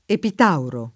Epitauro [ epit # uro ] → Epidauro